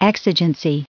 added pronounciation and merriam webster audio
1480_exigency.ogg